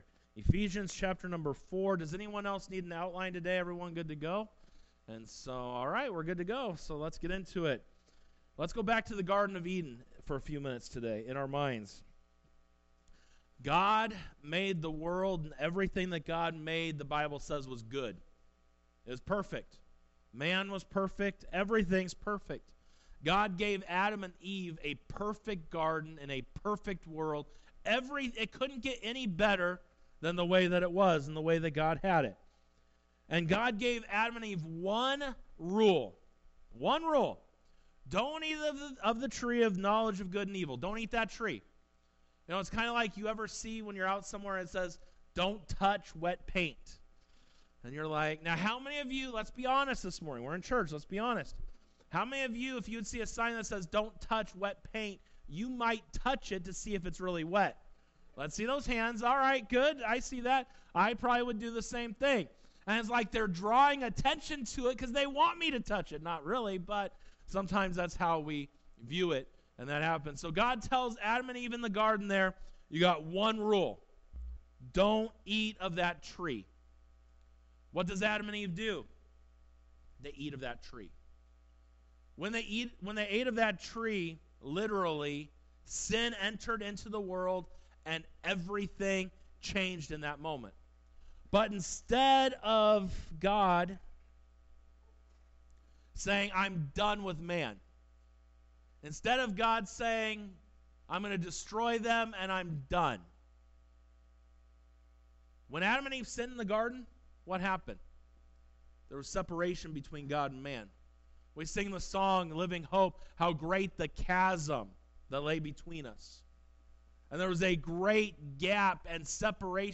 Sunday Worship Service 02:09:25 - Building Bridges, Not Walls by vbcchino